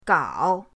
gao3.mp3